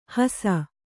♪ hasa